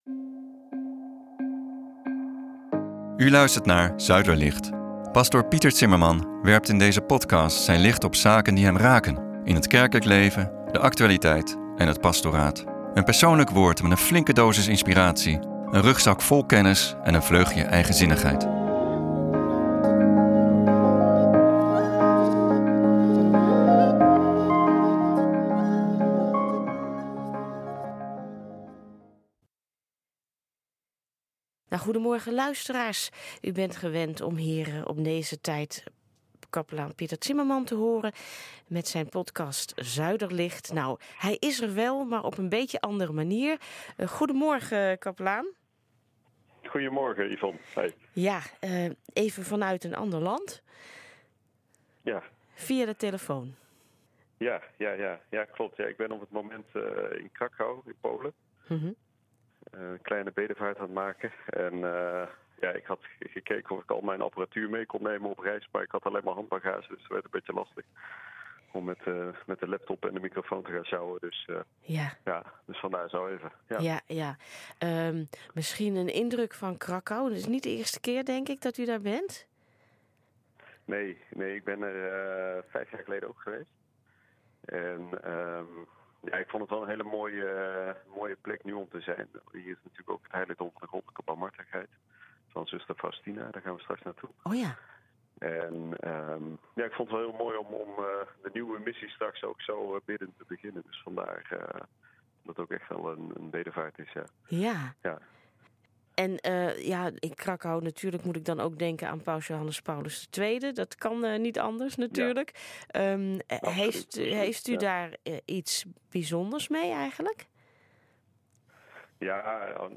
Live vanuit Krakau